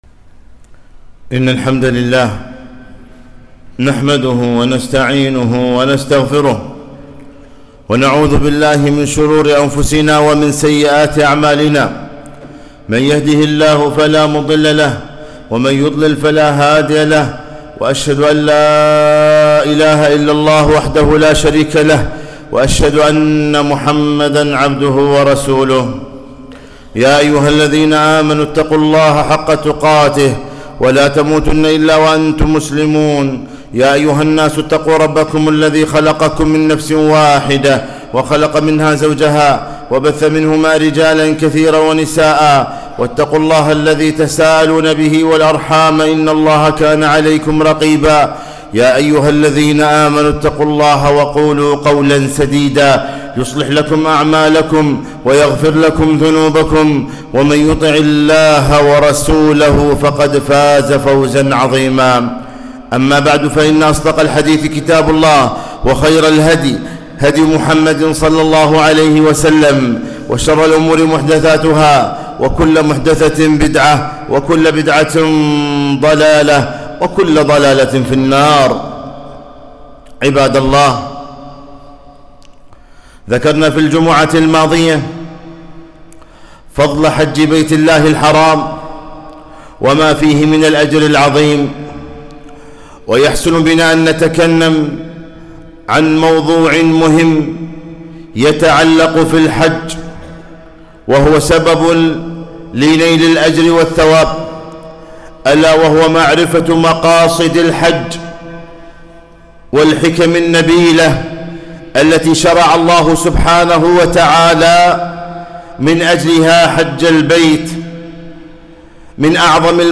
خطبة - مقاصد الحج